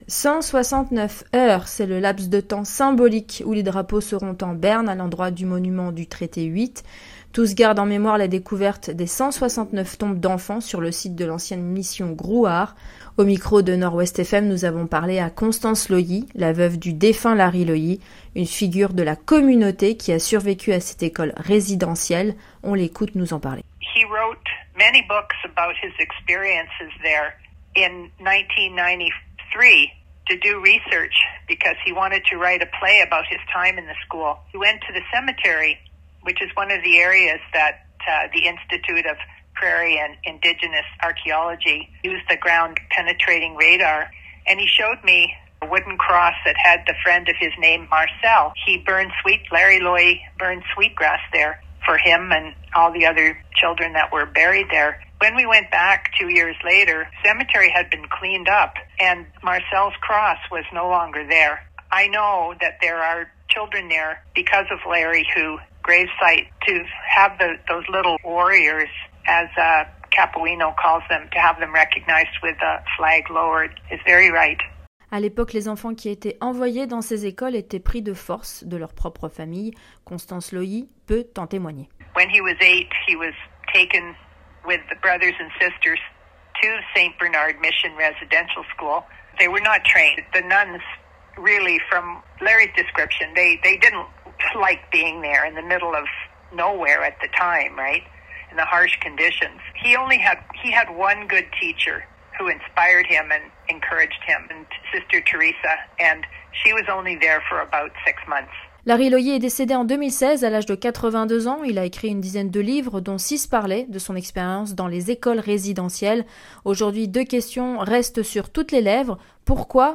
Un reportage de notre journaliste